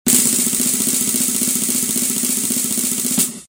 Звуки ожидания
Барабаны зовут